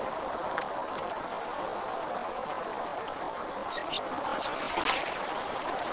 Friedhof der Namenlosen.
Hierbei wird eine Frage gestellt und stillschweigend etwa 6 - 10 Sekunden gewartet.